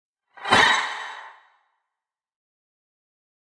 Descarga de Sonidos mp3 Gratis: whoosh 11.